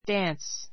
dance 小 A1 dǽns ダ ン ス ｜ dɑ́ːns ダ ーン ス 名詞 ❶ 踊 おど り , ダンス a square [folk] dance a square [folk] dance スクエア[フォーク]ダンス May I have this dance (with you)?